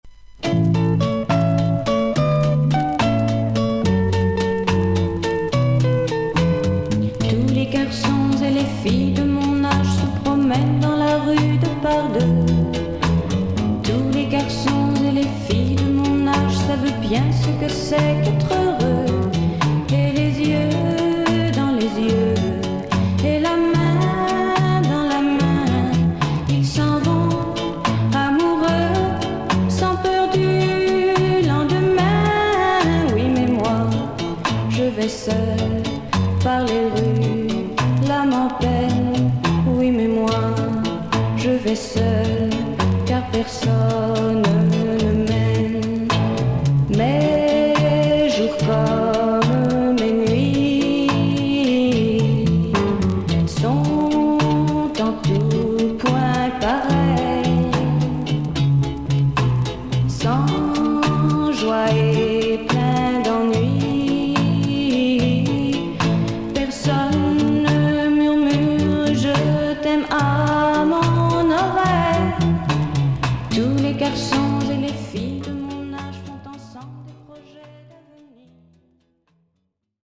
少々サーフィス・ノイズの箇所あり。クリアな音です。
フランスの女性シンガー/ソング・ライター。